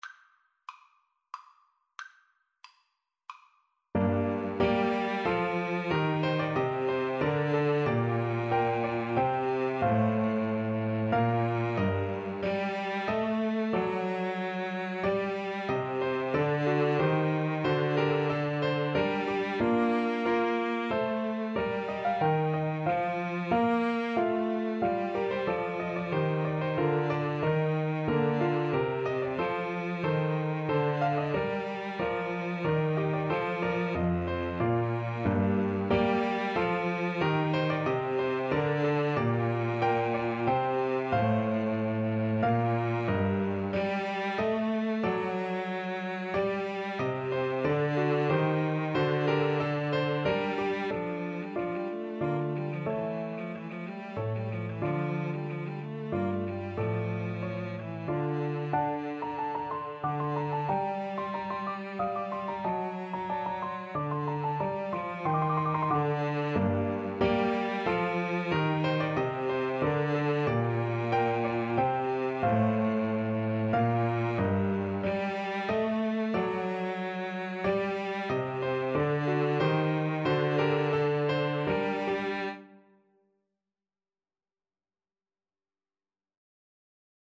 3/2 (View more 3/2 Music)
Allegro Moderato = c. 92 (View more music marked Allegro)
Classical (View more Classical Violin-Flute Duet Music)